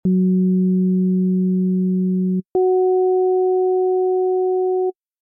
You should now hear a higher tone joining your original tone when a note is played.